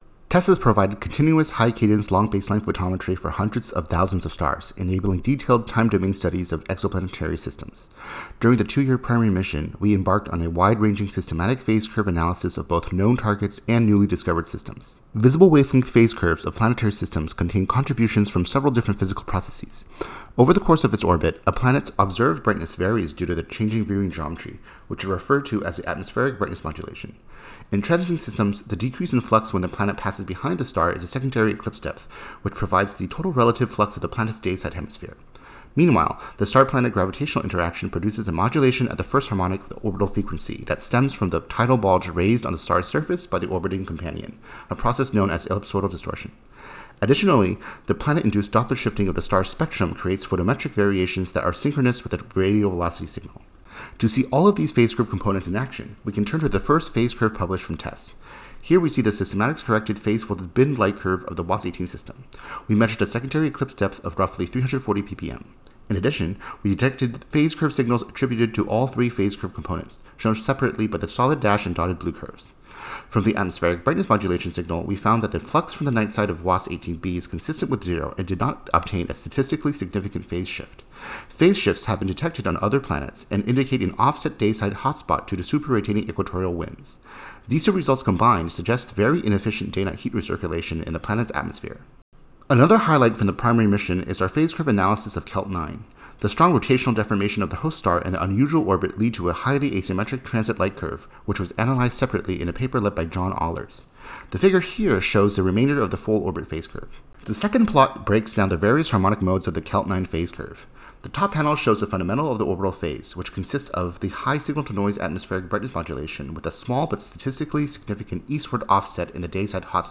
By measuring the phase curve, we probed fundamental physical quantities, such as the planet’s dayside temperature, Bond albedo, and day-night heat recirculation efficiency, as well as the host star’s response to the mutual star-planet gravitational interaction. In this talk, I provide an overview of the main results from the Primary Mission.